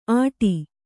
♪ āṭi